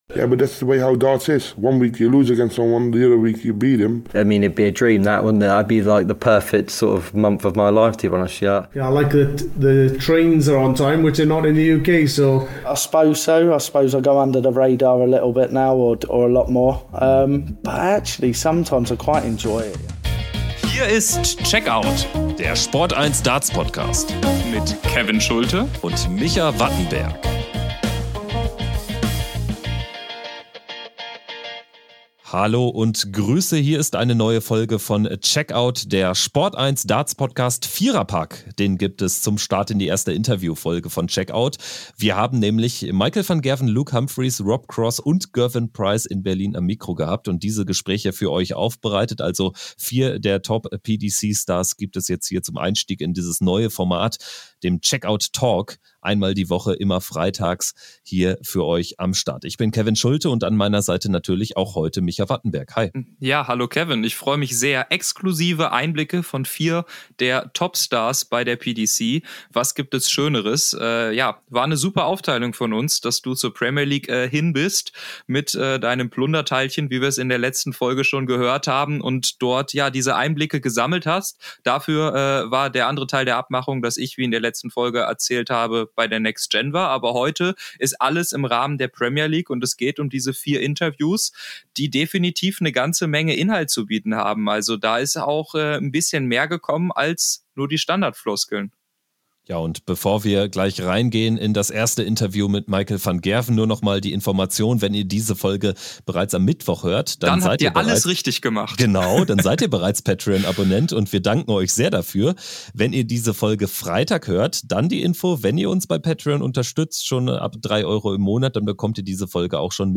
die-fantastischen-vier-premier-league-interviews.mp3